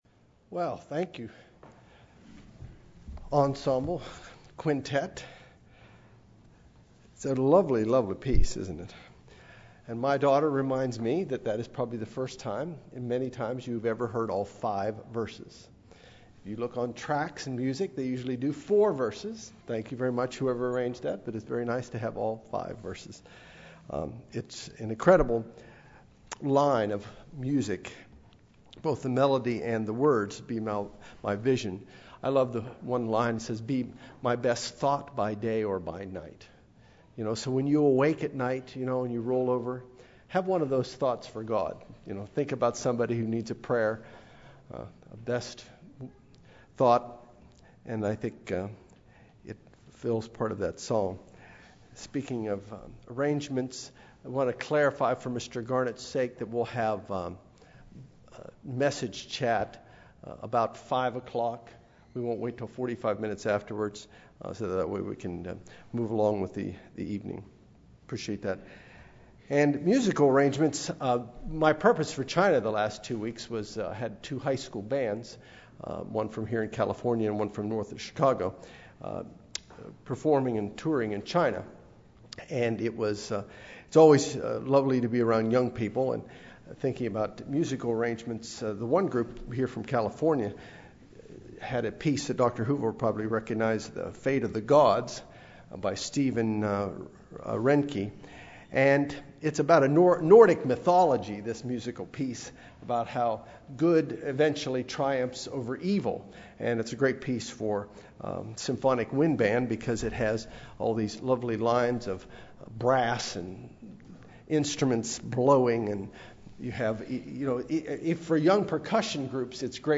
Given in Los Angeles, CA
UCG Sermon